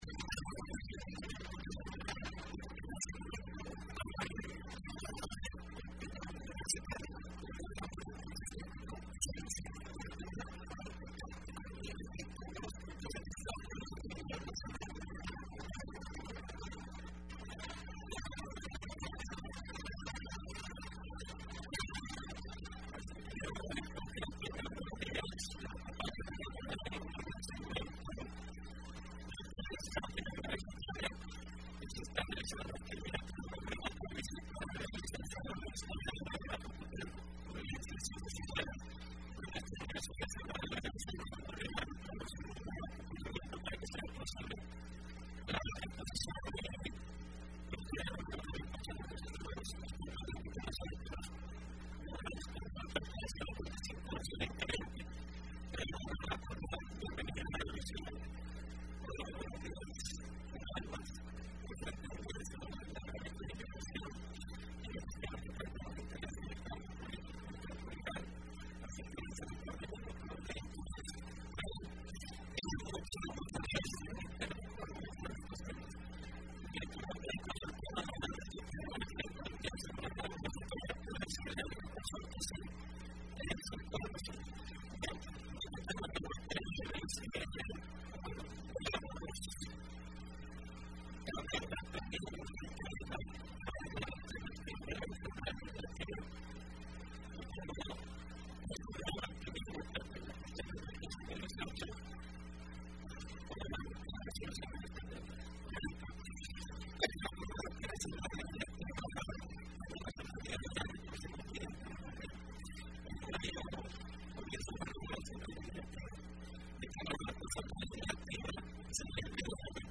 Entrevista Opinión Universitaria (15 de abril de 2015): Trabajo Asamblea Legislativa 2015-2018